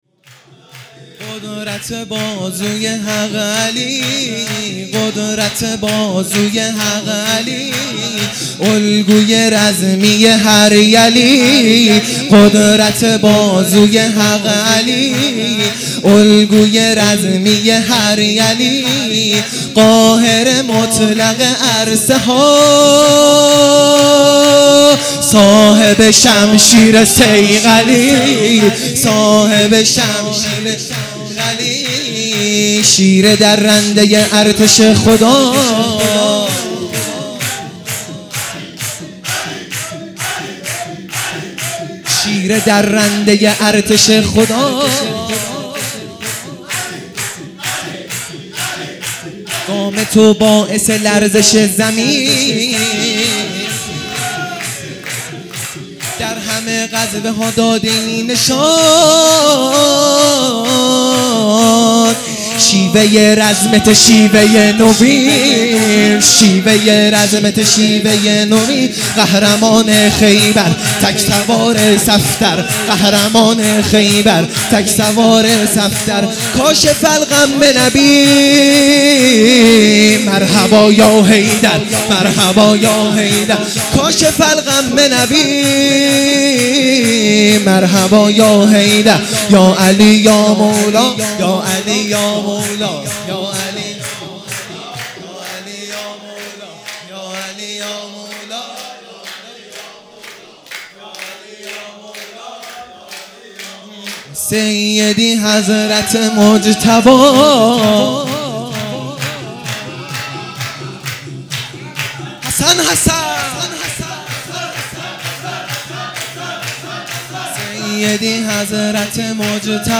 سرود
میلاد امام هادی علیه السلام